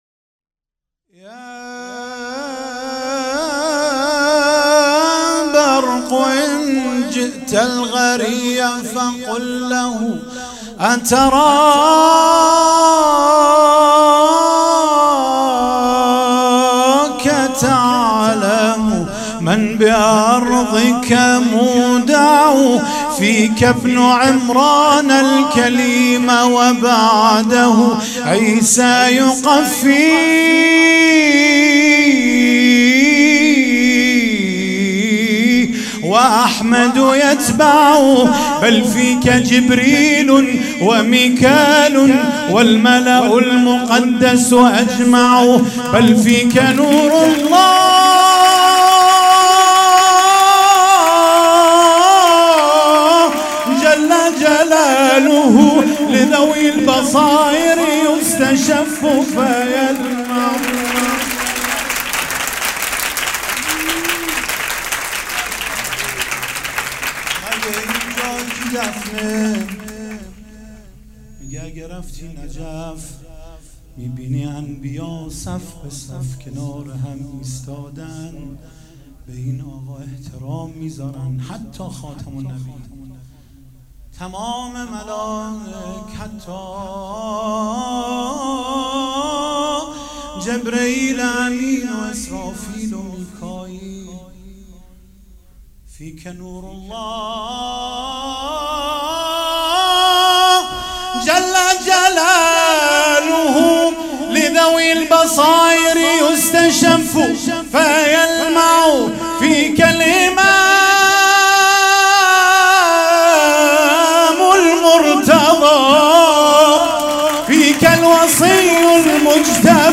28 اسفند 97 - حسینیه بیت العباس - مدح عربی